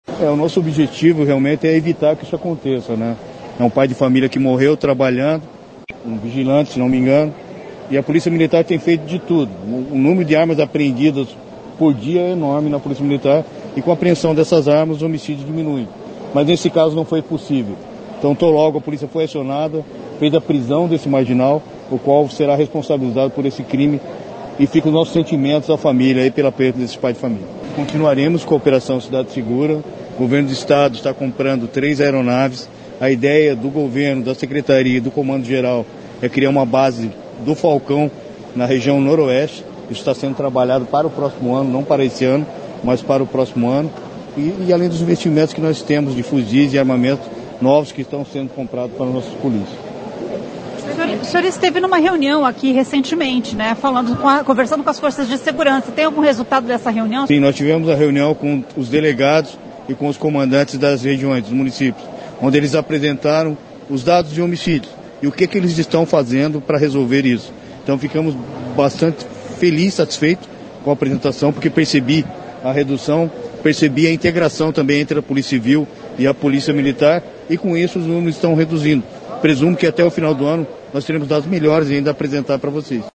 Em coletiva de imprensa nesta quinta-feira (5) em Maringá, o secretário de Segurança Pública do Paraná, Hudson Leôncio Teixeira, disse que no ano que vem a região noroeste poderá ter uma base do projeto Falcão, que reforça a segurança com o trabalho de drones e aeronaves.